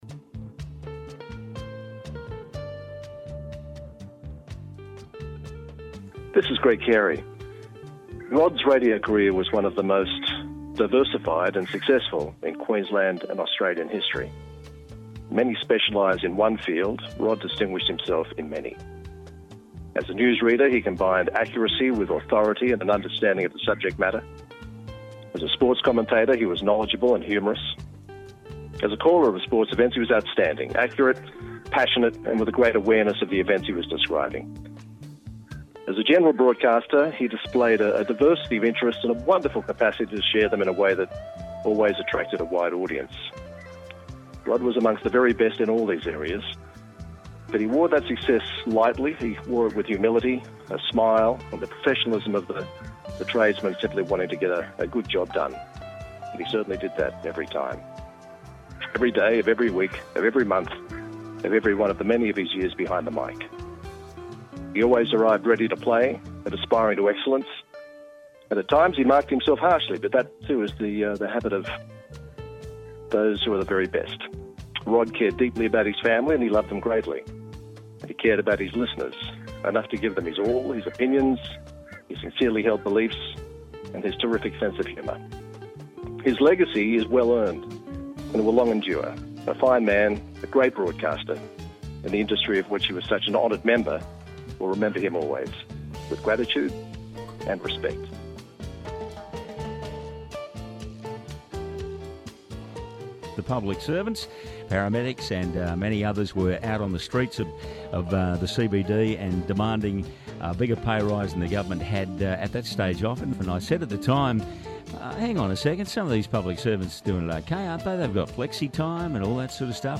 Join 4BC presenters past and present